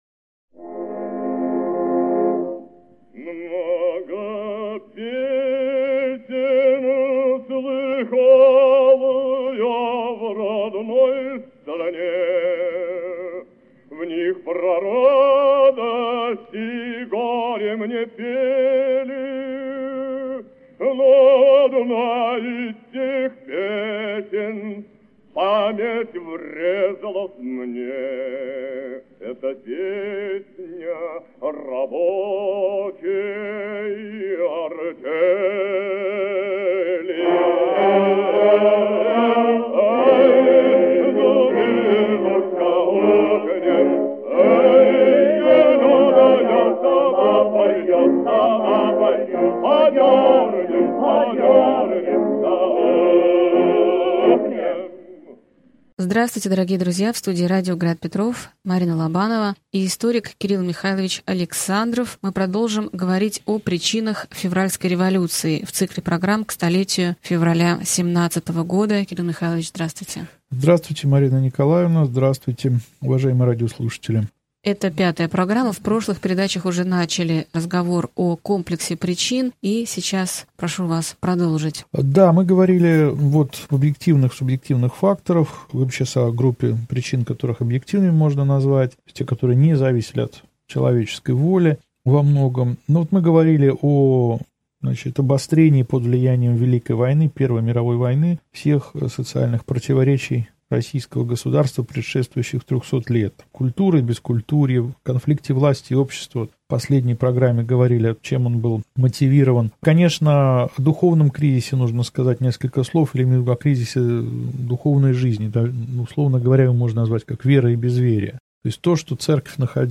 Аудиокнига Февральская революция и отречение Николая II. Лекция 5 | Библиотека аудиокниг